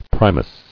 [pri·mus]